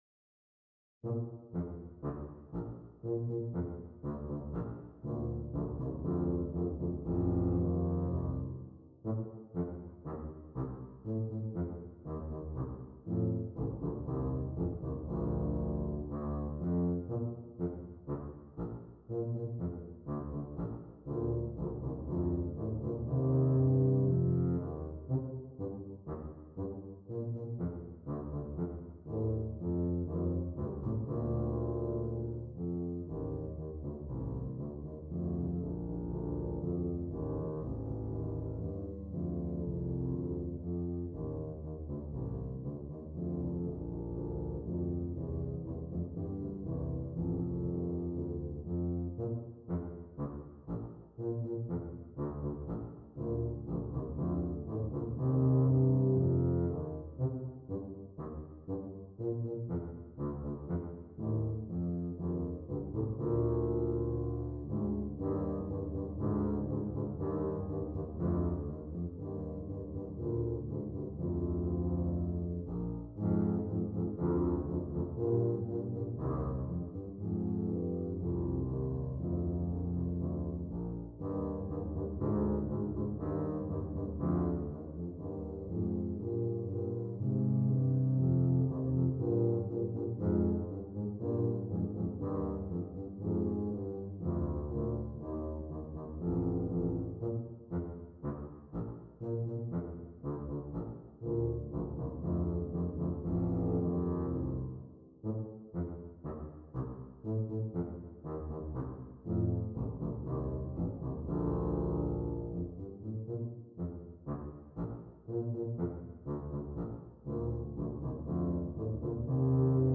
Brass Band
2 Tubas